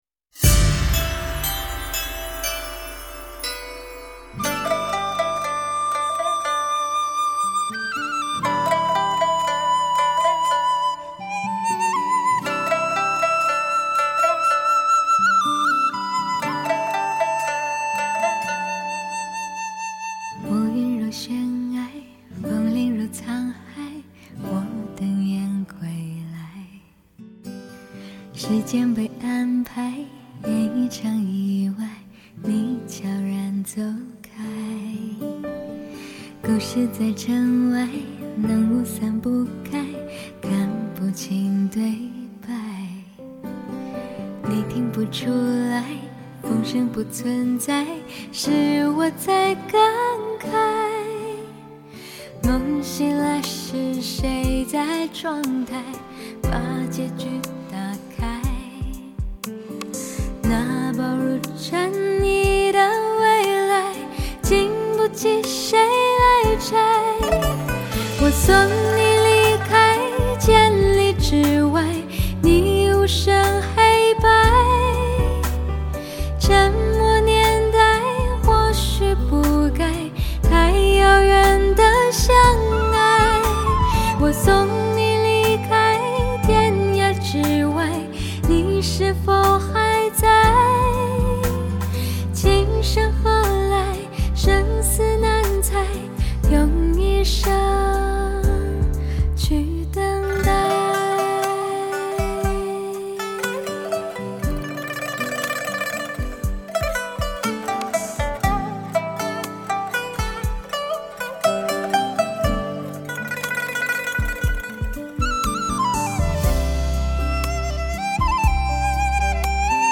众里寻她千百度，如此令人舒心的声音实在是上天对我们的恩宠，柔情、清新又不失穿透力。
古筝、二胡、笛子